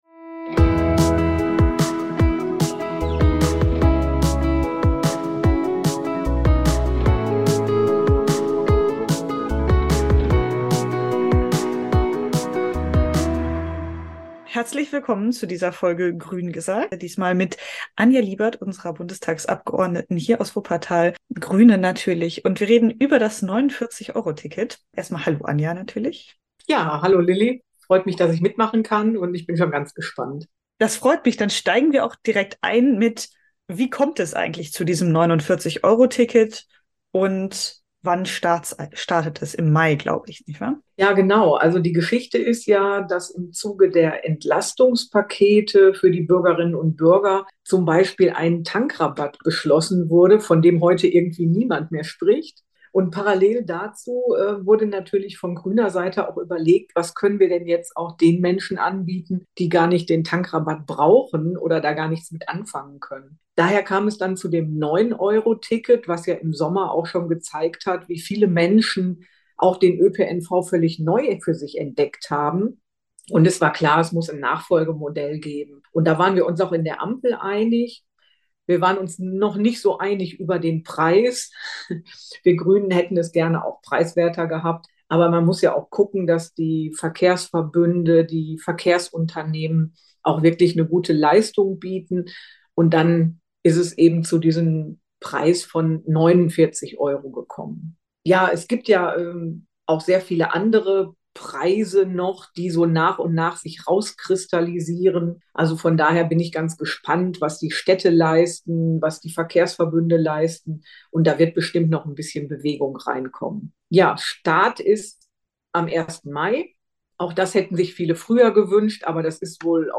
Wir sprechen mit unserer Bundestagsabgeordneten Anja Liebert darüber warum das Ticket so eine große Bedeutung hat, was noch besser werden kann und welche Möglichkeiten das Ticket nun eröffnet.